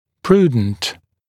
[‘pruːdnt][‘пру:днт]благоразумный, предусмотрительный, разумный